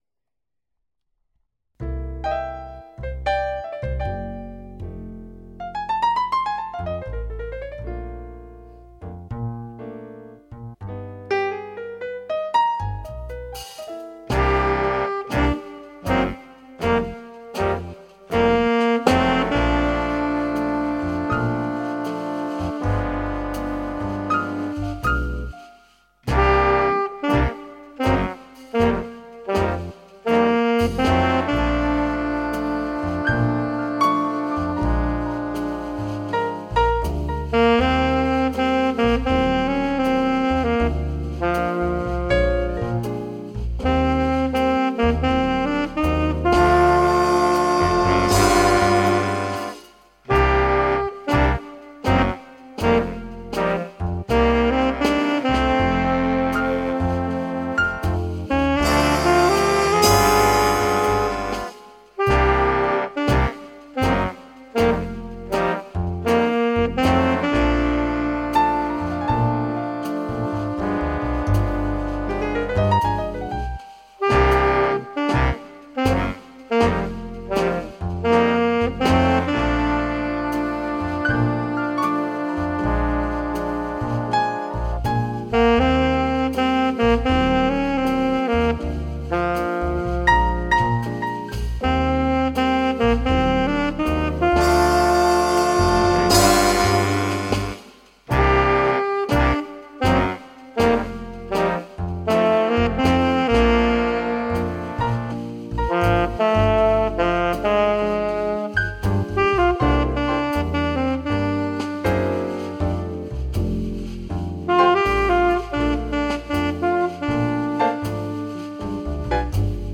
Alto Sax and Violin.